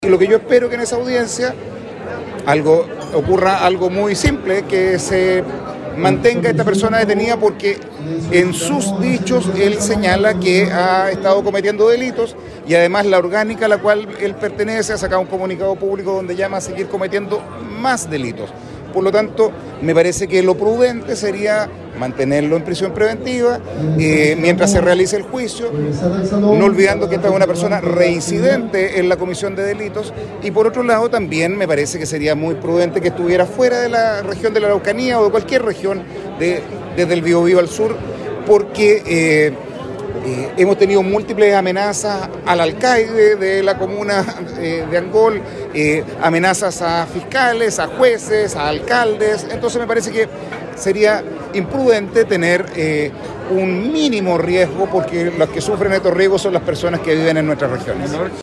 En el marco del evento Impulsa 2022, organizado por la Cámara del Comercio y Producción del Biobío, el Gobernador Regional, Rodrigo Díaz se refirió a las solicitudes planteadas como desafíos desde la zona.